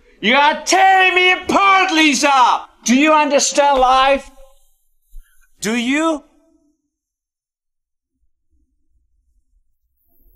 theroom1-dry.wav